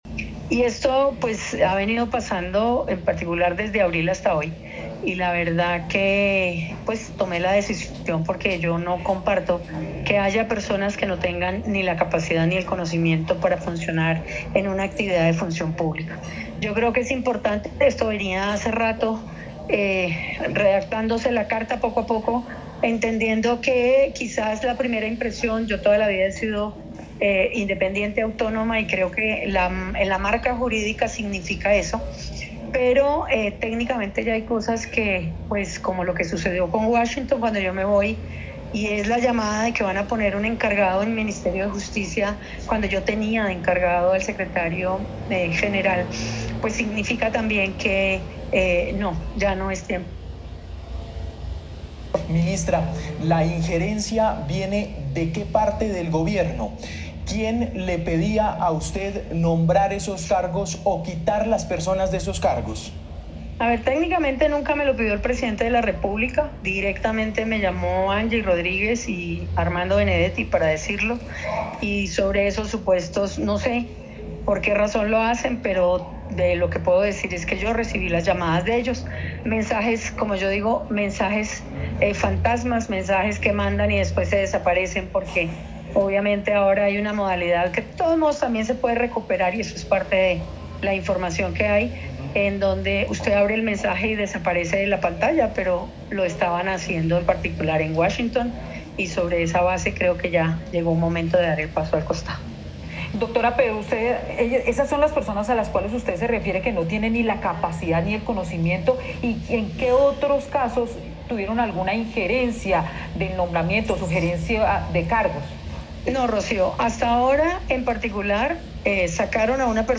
ENTREVISTA
Angela-Maria-Buitrago-explica-la-razones-de-su-renuncia-al-Ministerio-de-Justicia.mp3